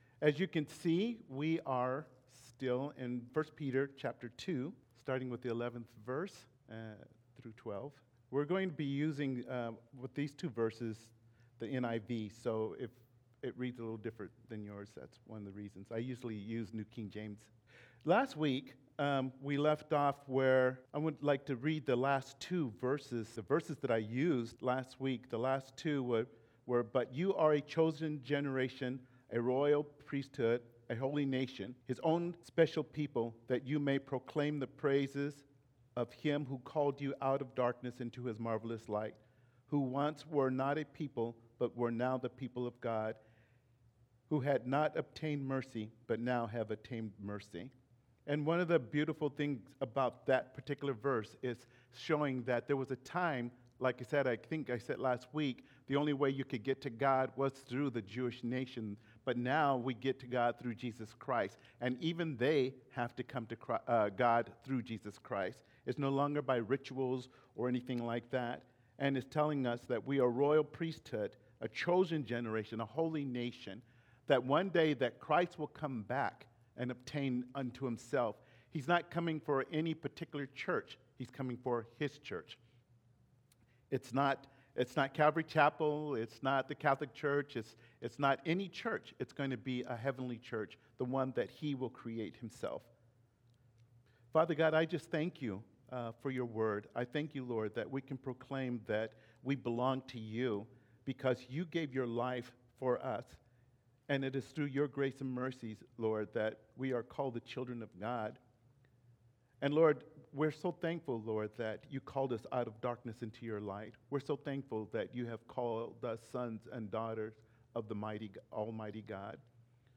Passage: 1 Peter 2:10-1:11 Service Type: Sunday Morning